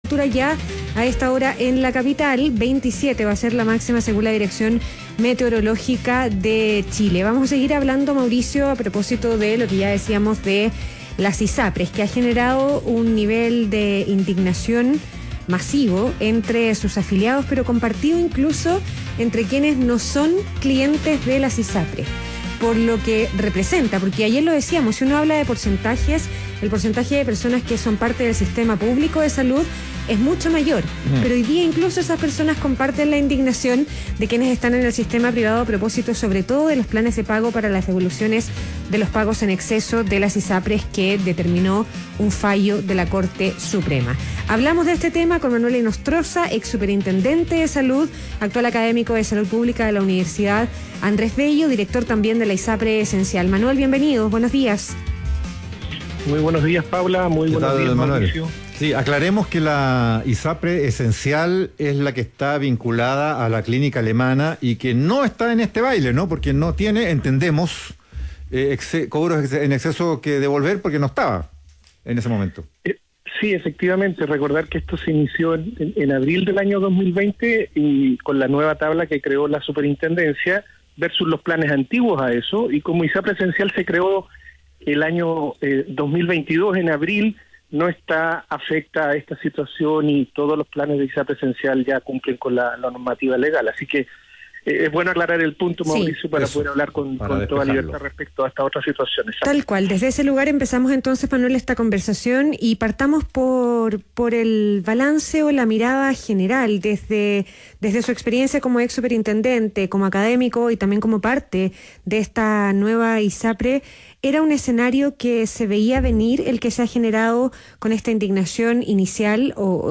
ADN Hoy - Entrevista a Manuel Inostroza, exsuperintendente de Salud